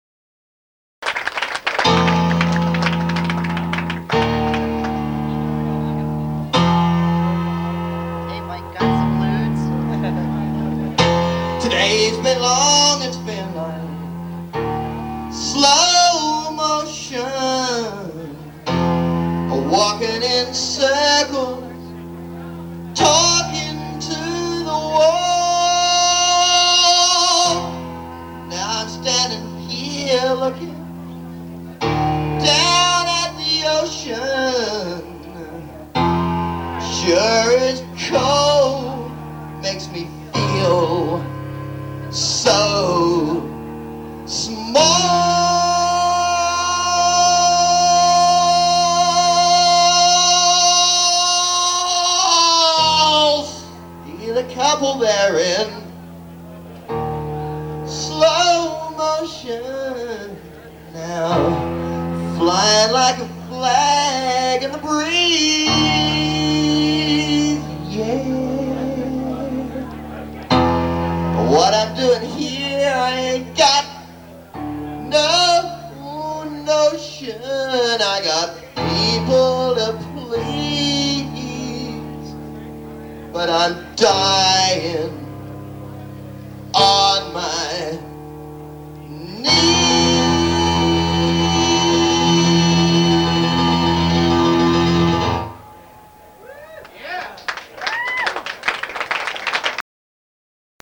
This was recorded in the audience at the Khyber on 8/19/93.